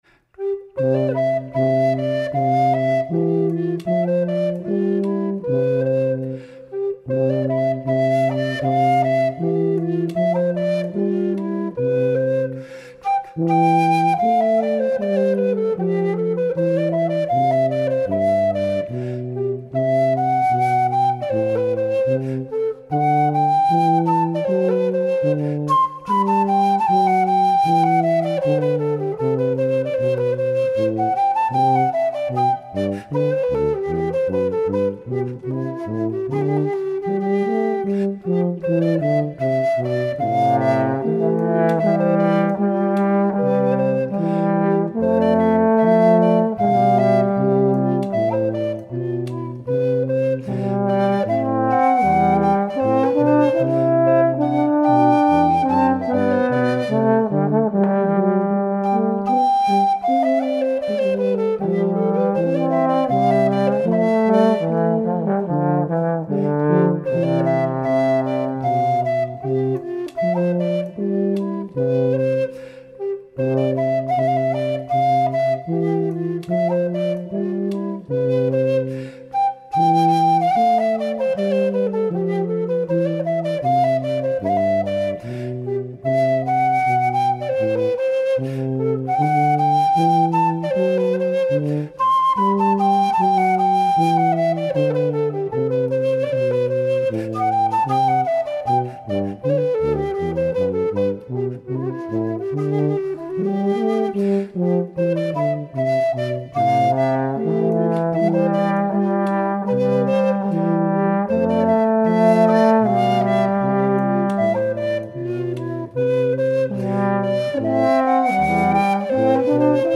Eines der berühmtesten Choralvorspiele von
ursprünglich in Es-Dur,